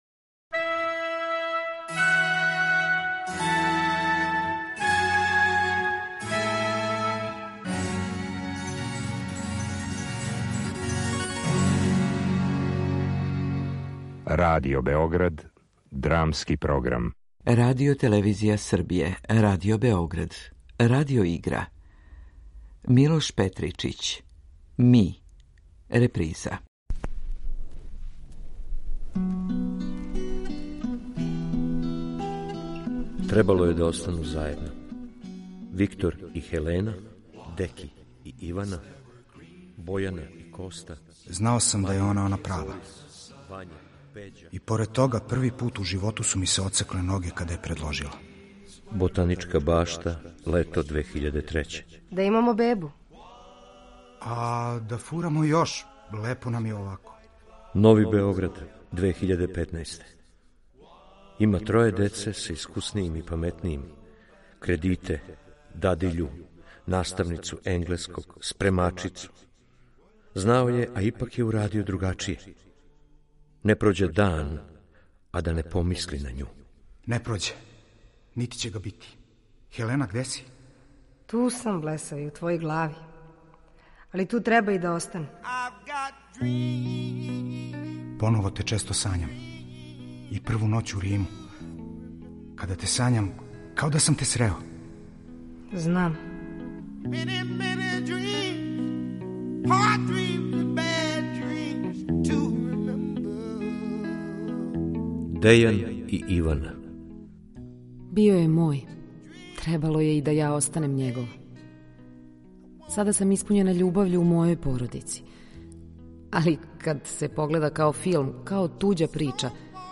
Радио-игра
drama.mp3